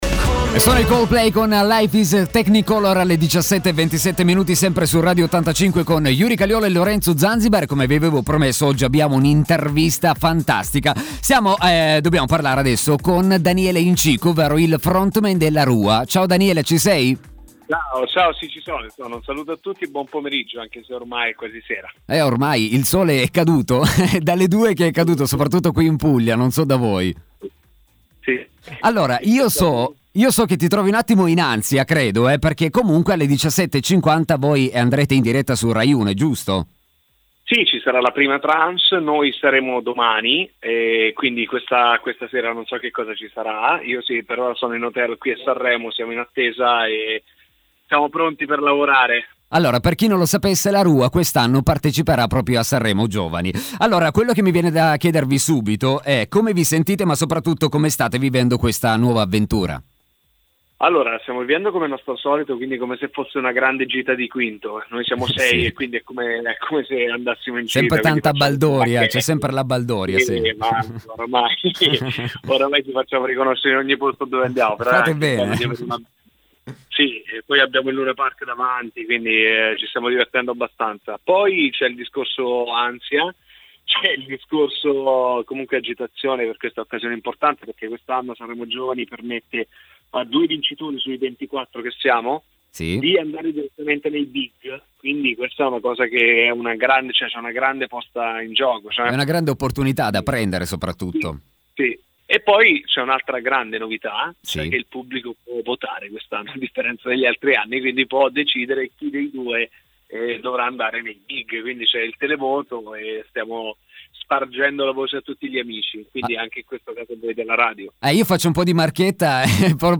Top Player – Intervista ai La Rua